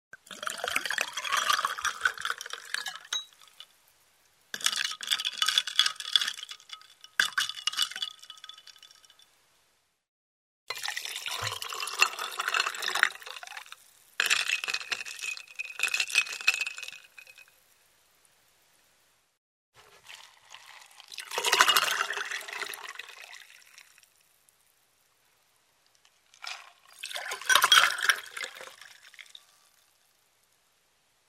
Звуки ресторана
Наливают напиток в стакан с кубиками льда